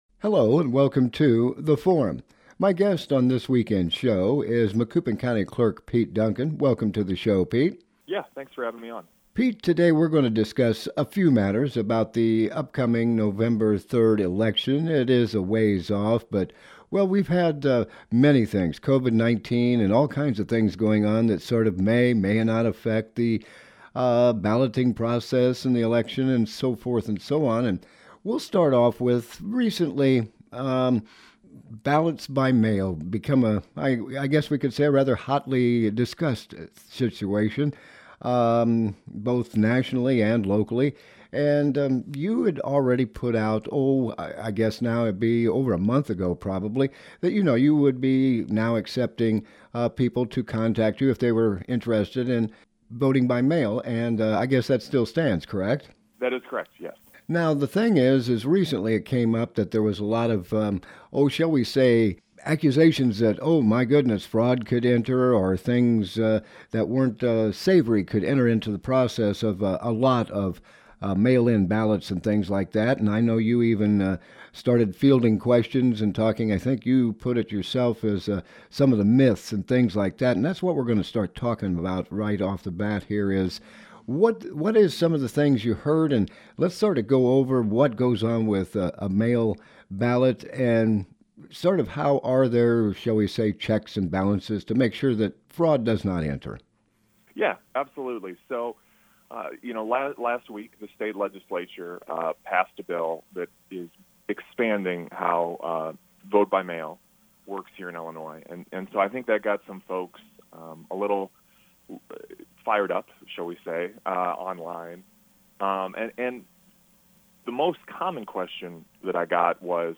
Topic: November 3rd Election Guest: Pete Duncan - Macoupin County Clerk